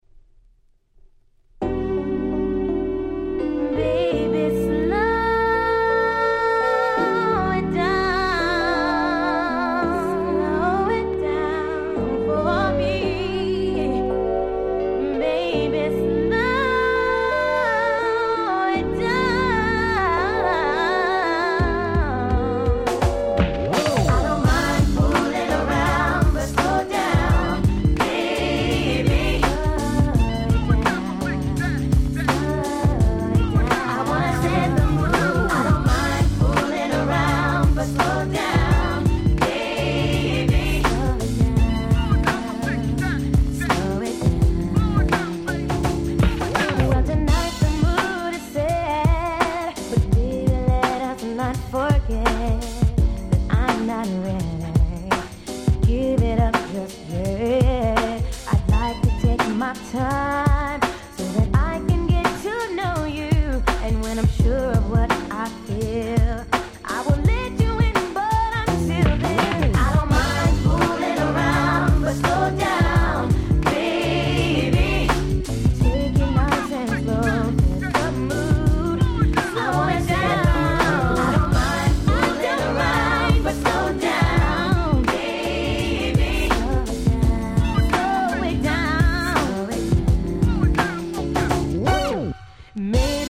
94' Very Nice Slow Jam !!
甘く切ない、そしてエロティックな最高のSlow。